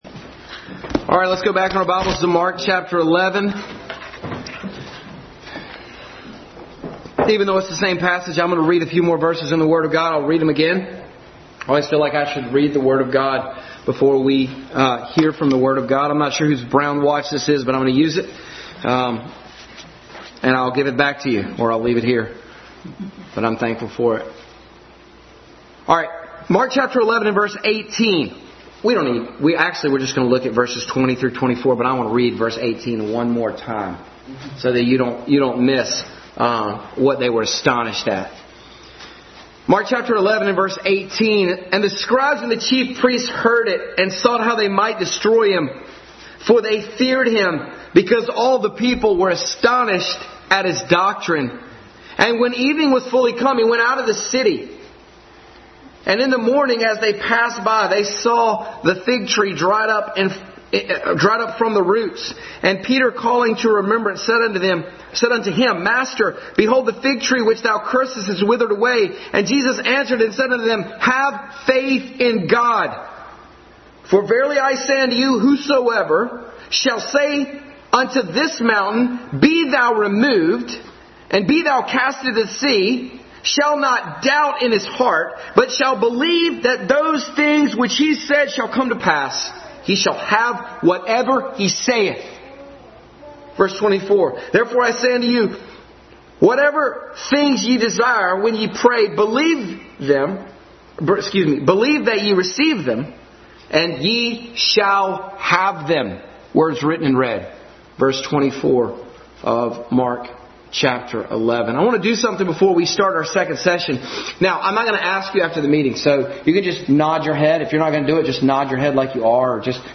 Passage: Mark 11:1-24, Matthew 5:23 Service Type: Family Bible Hour